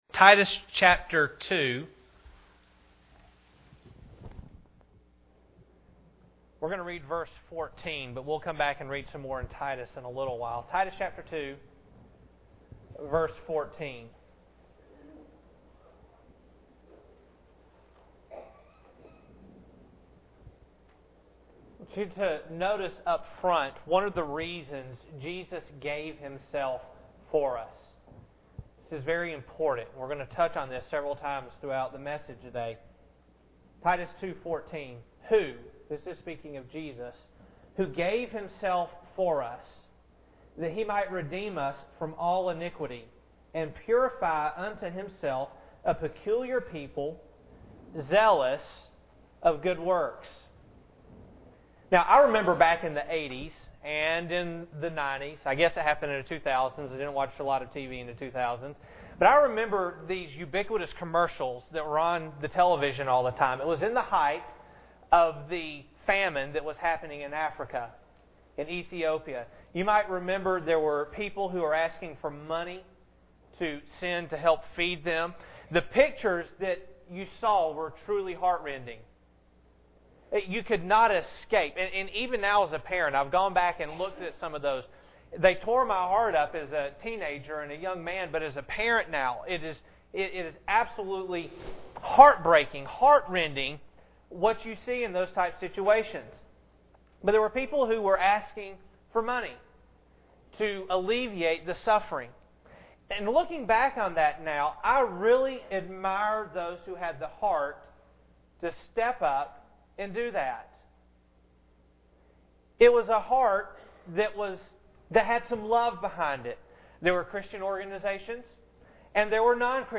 Good Works Passage: Titus 2:14 Service Type: Sunday Morning Bible Text